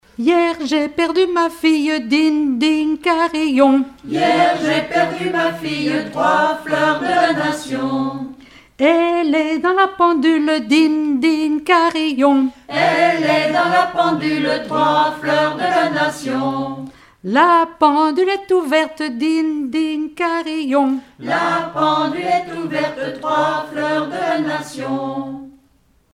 danse : rondes enfantines (autres)
Rassemblement de chanteurs
Pièce musicale inédite